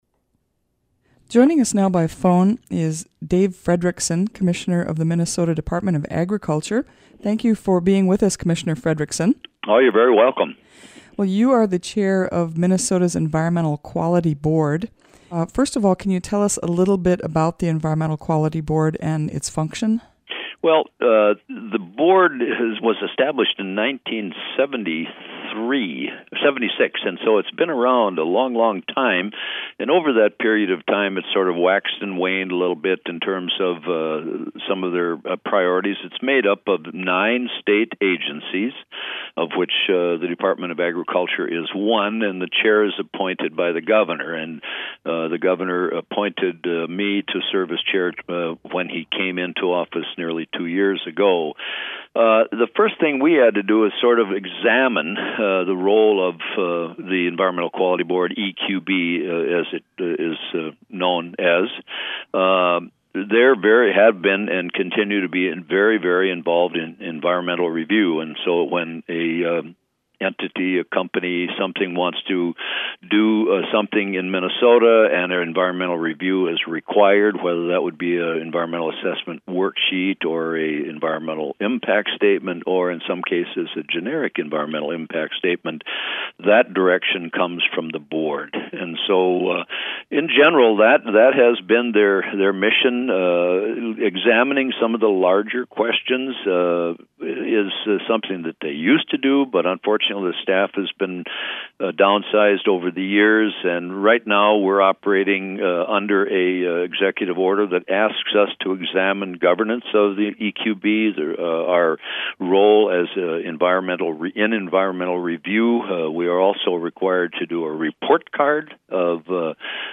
(Click on audio mp3 above to hear a recent interview with Minnesota Department of Agriculture Commissioner Dave Frederickson, chair of the Environmental Quality Board (EQB), on this topic.)